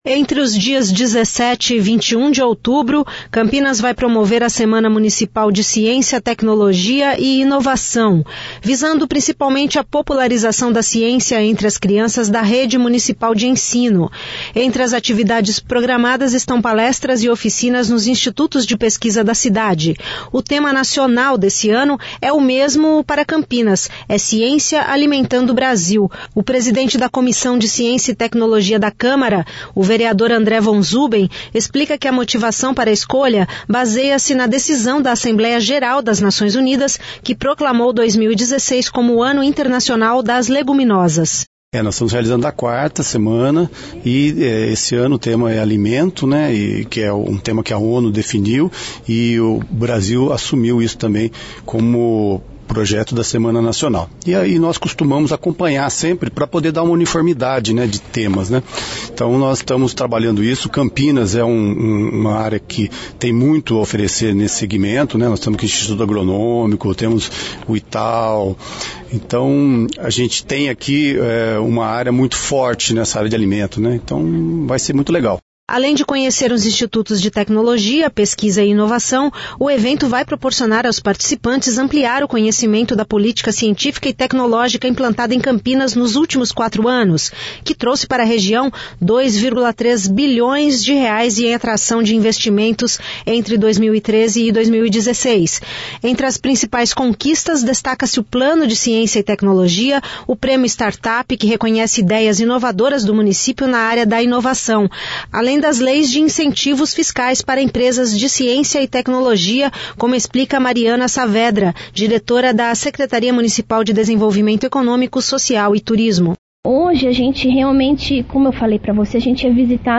O Presidente da Comissão de Ciência e Tecnologia da Câmara, o vereador André Von Zuben explica que a motivação para a escolha baseia-se na decisão da Assembleia Geral das Nações Unidas, que proclamou 2016 como o Ano Internacional das Leguminosas.